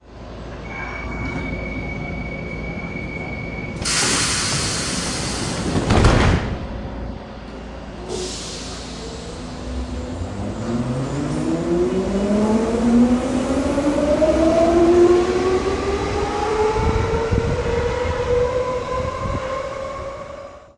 北京西站新冠广播
描述：录音时间：2021年7月30日 上午地点：北京西站 候车大厅设备：Sony－D100
标签： 火车站 广播 新冠
声道立体声